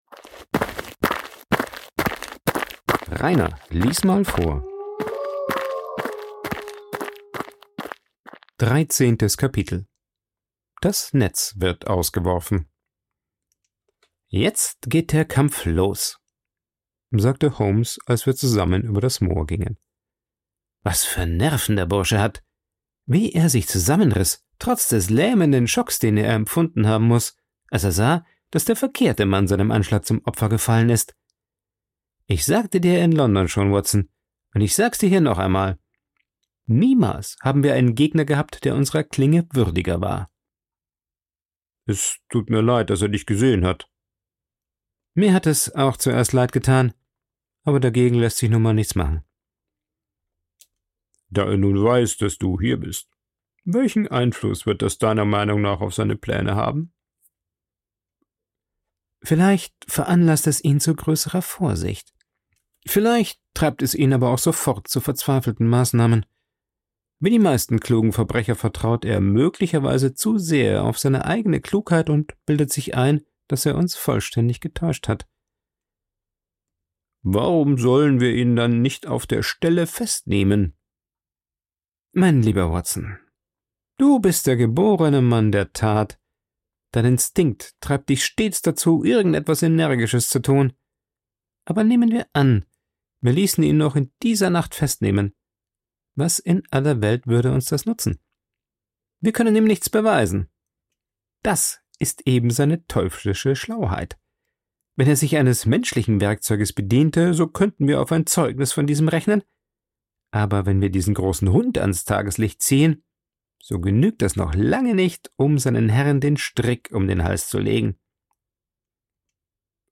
In Wirklichkeit bereitet Sherlock aber die Falle für den Mörder vor. Vorgelesen
aufgenommen und bearbeitet im Coworking Space Rayaworx, Santanyí, Mallorca.